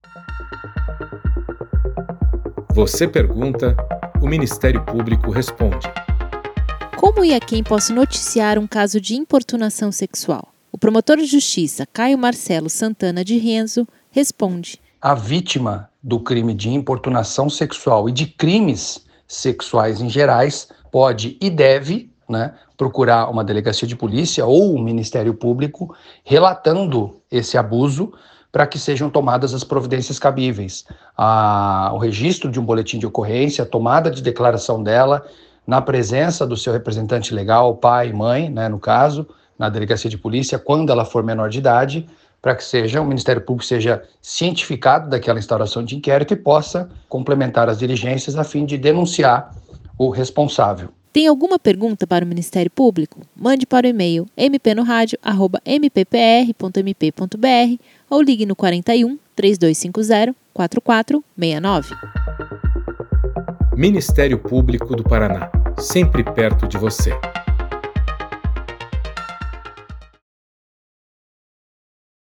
O MP Responde esclarece dúvidas sobre importunação sexual, respondidas pelo promotor de Justiça Caio Marcelo Santana Di Rienzo, que foi responsável por denúncia criminal contra um motorista de van que praticou esse crime em Marechal Cândido Rondon.